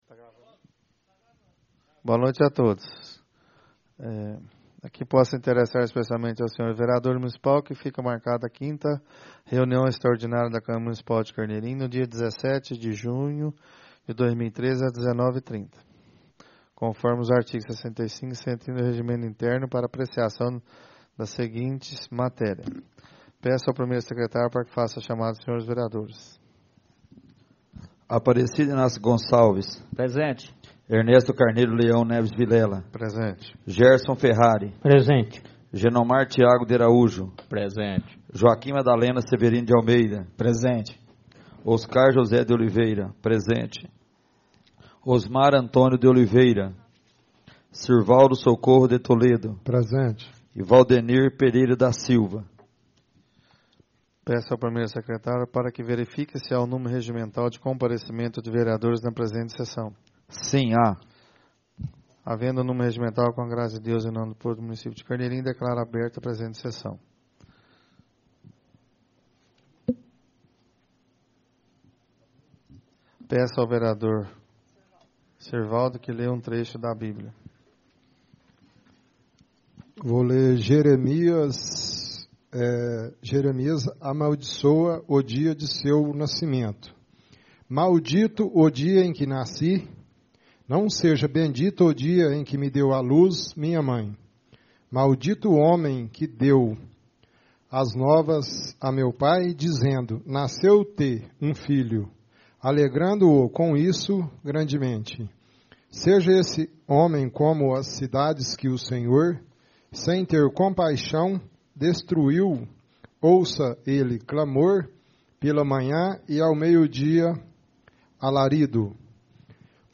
Realizada no dia 17 de Junho de 2013, na sala de sessões da Câmara Municipal de Carneirinho, Estado de Minas Gerais.